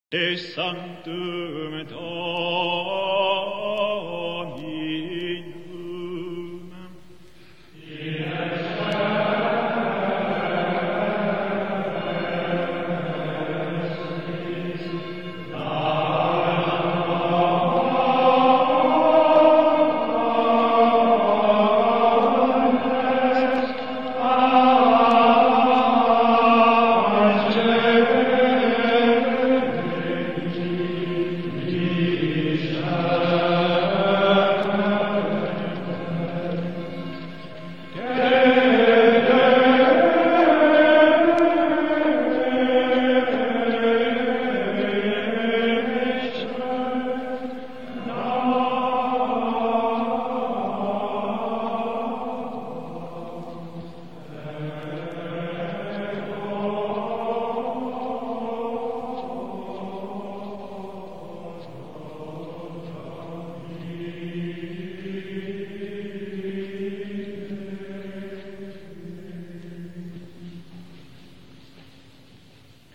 Франция, Запись 1950 г.